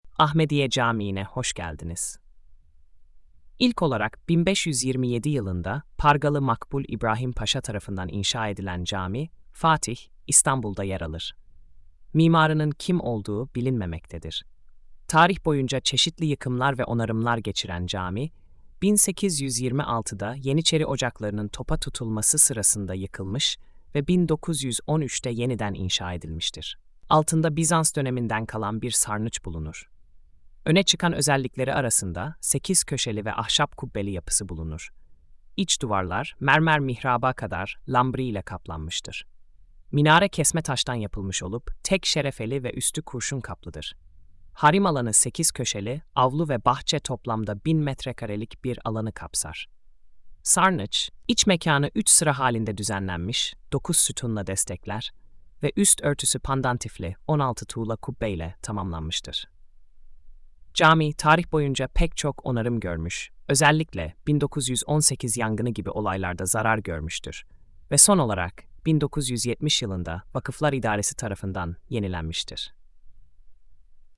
Sesli Anlatım: